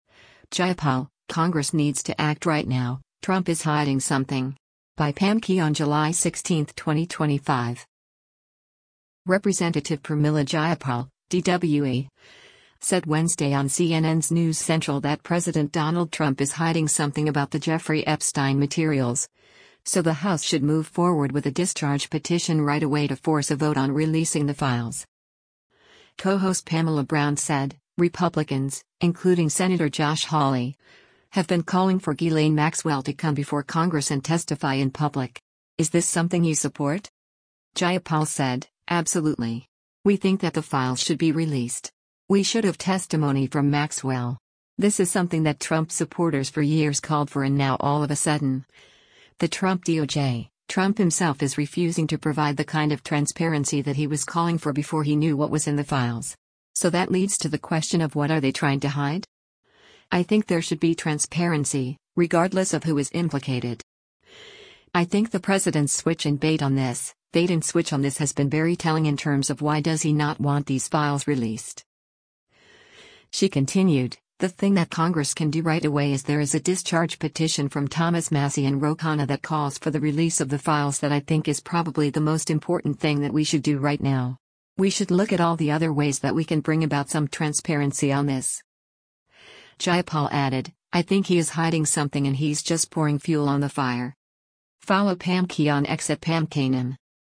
Representative Pramila Jayapal (D-WA) said Wednesday on CNN’s “News Central” that President Donald Trump is “hiding something” about the Jeffrey Epstein materials, so the House should move forward with a discharge petition “right away” to force a vote on releasing the files.